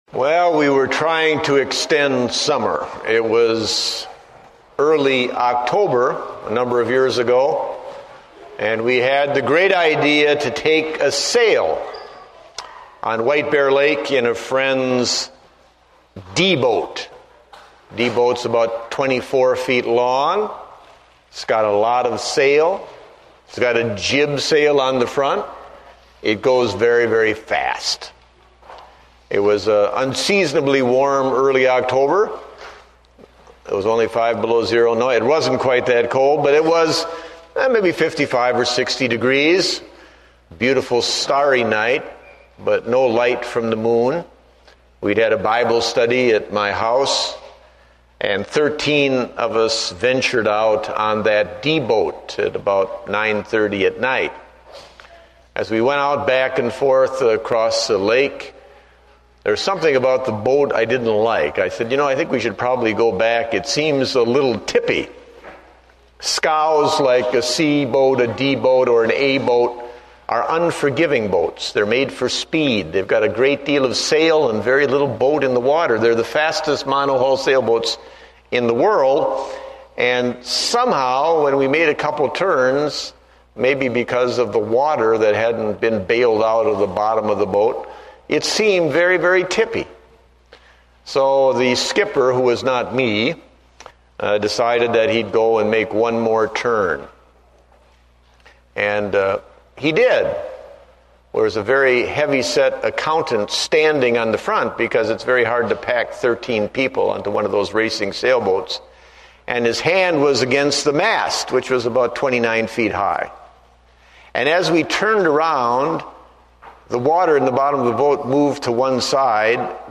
Date: August 3, 2008 (Morning Service)